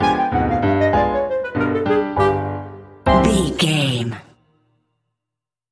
Aeolian/Minor
flute
oboe
strings
circus
goofy
comical
cheerful
perky
Light hearted
quirky